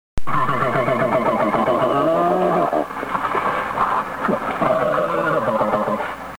Voice
Adult vocalizations vary from a loud growl when fighting, to a woof-woof to warn cubs of danger, to a whimper to call cubs. Cubs can produce shrill howls when lonely or frightened.
american-black-bear-call.mp3